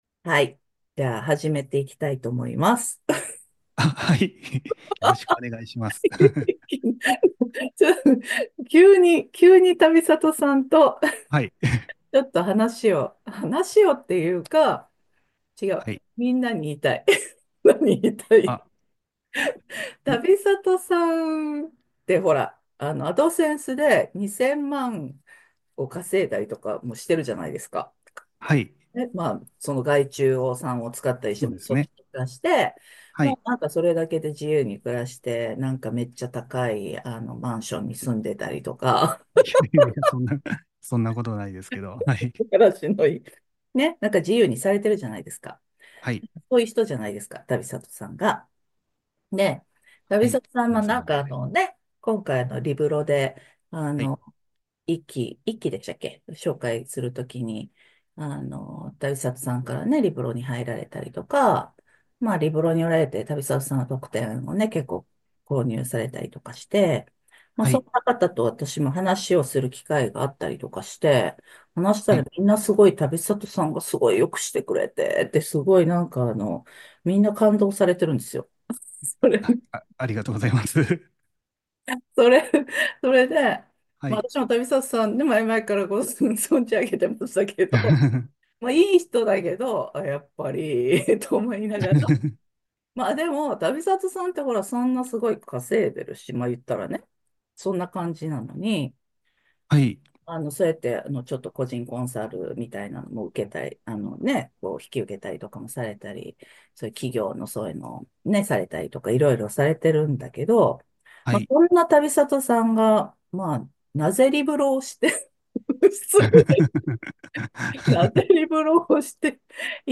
笑 P.P.S. ってか、 なぜいきなり対談音声？